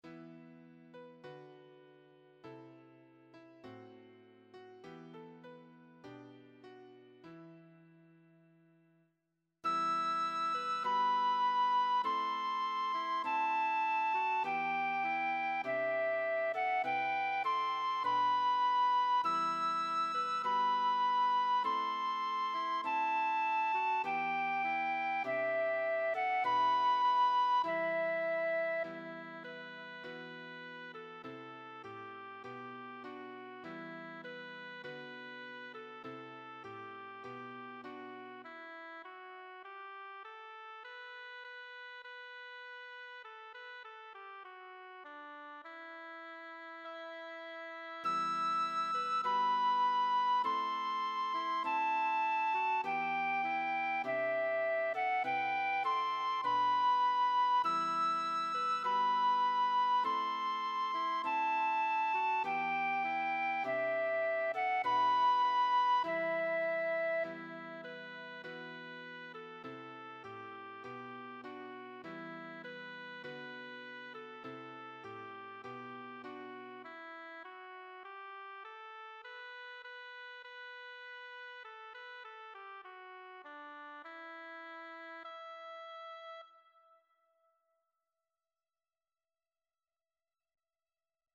Flauta
Abraham-y-El-Yo-Soy-Piano-FL.mp3